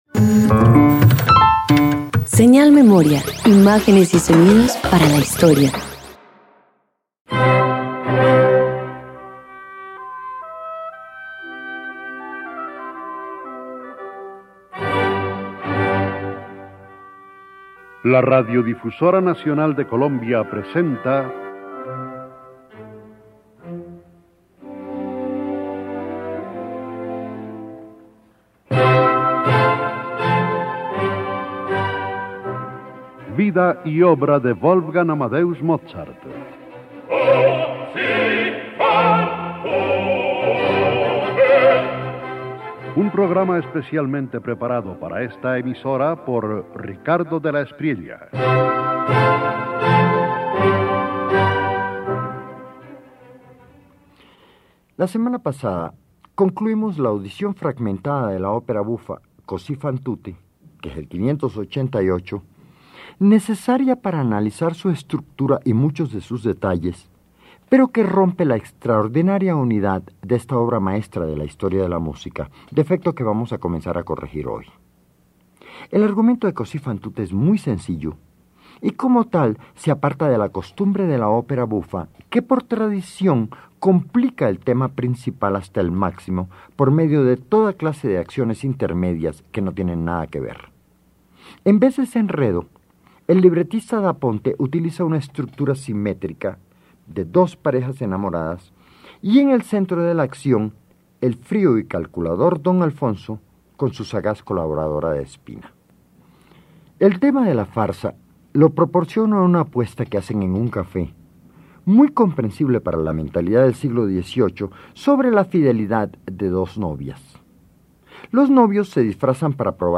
310 Opera Cosi Fan Tutte  Parte VI_1.mp3